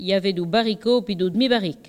Localisation Sallertaine
collecte de locutions vernaculaires
Catégorie Locution